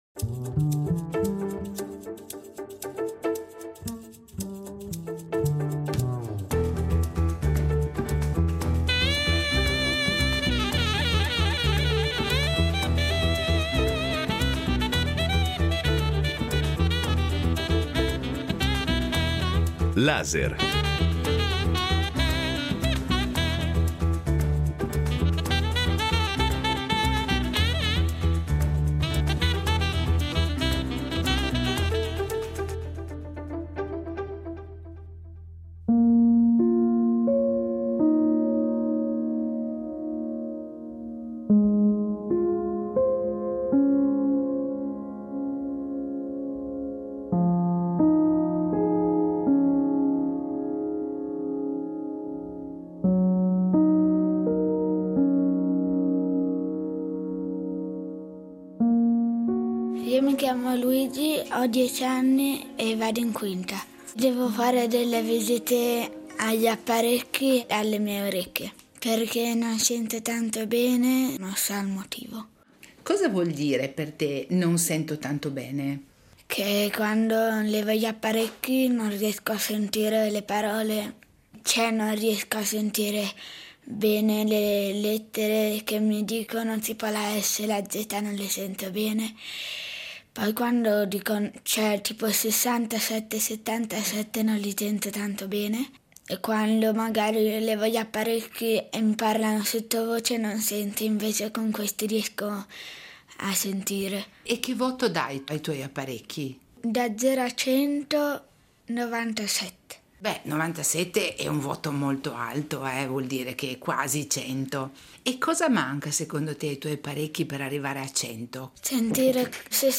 Un reportage all’interno del reparto di Audiovestibologia di Varese